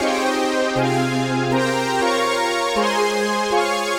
Index of /musicradar/80s-heat-samples/120bpm
AM_VictorPad_120-C.wav